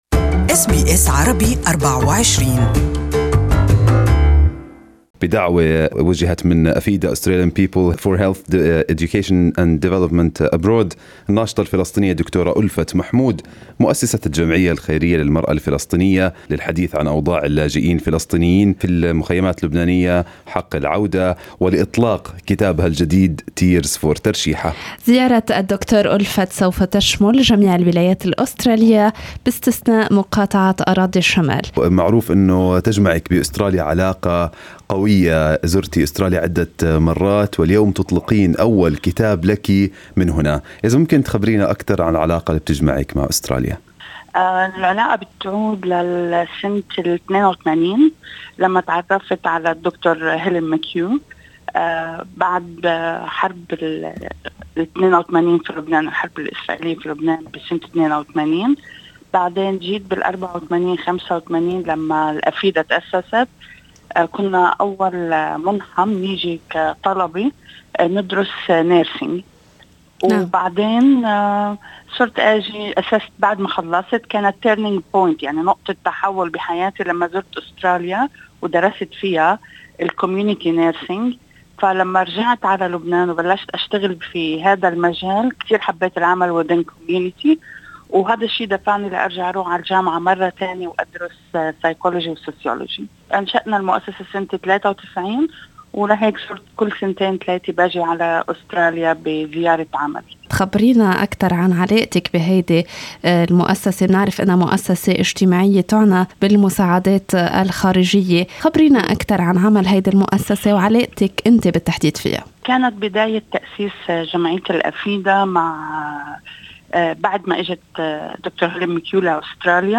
المقابلة مرفقة بالصورة أعلاه.